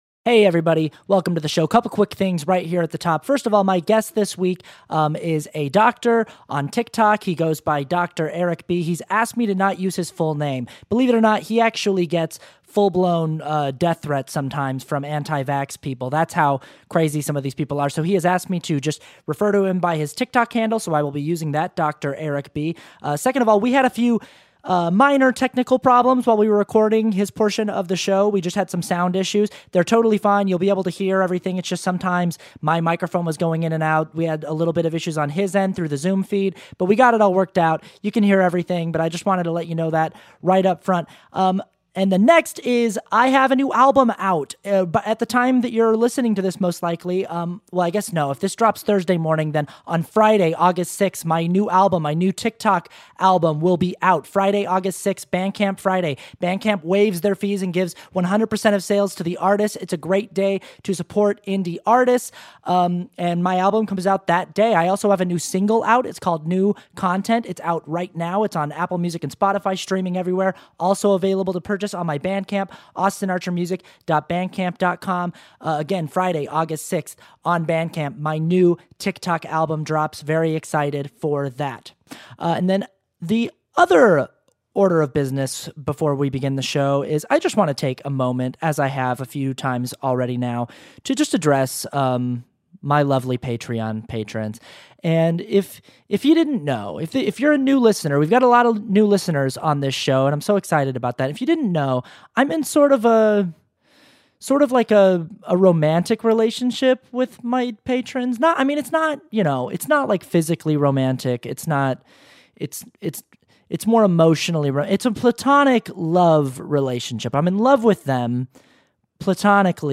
A report from a medical professional who has been working in the trenches of the COVID-19 pandemic. The data behind where we're currently at in the battle to contain this deadly virus.